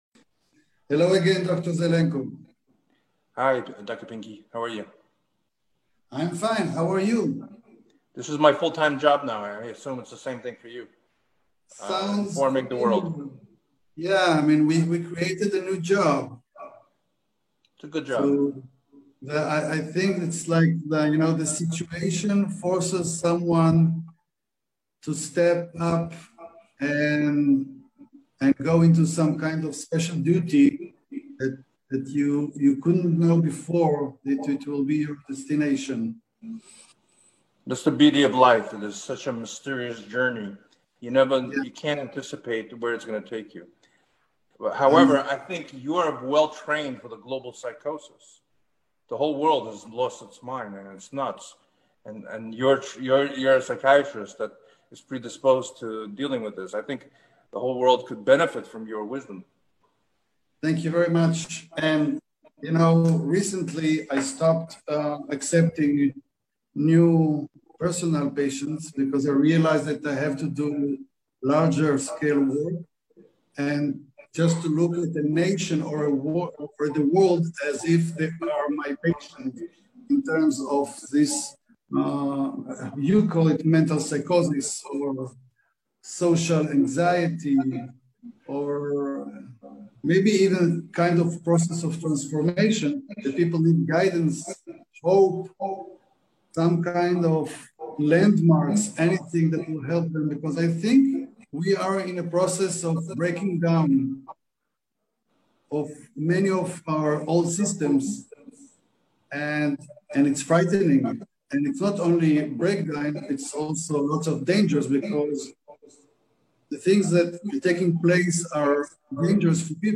ראיון שלישי וסופר חשוב וקריטי